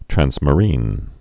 (trănsmə-rēn, trănz-)